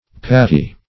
Search Result for " pattee" : The Collaborative International Dictionary of English v.0.48: Patt'e \Pat`t['e]"\, Pattee \Pat*tee"\, a. [F. patt['e], fem. patt['e]e, fr. patte paw, foot.